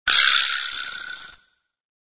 misc_rachet00.mp3